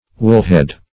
woolhead - definition of woolhead - synonyms, pronunciation, spelling from Free Dictionary Search Result for " woolhead" : The Collaborative International Dictionary of English v.0.48: Woolhead \Wool"head`\, n. (Zool.)